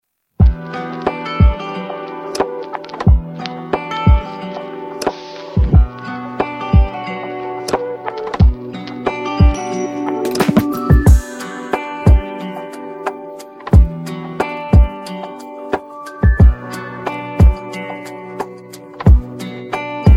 ویژگی‌های این موزیک بی‌کلام:
🎵 کیفیت صدای عالی و استودیویی
🎧 بدون افت کیفیت و نویز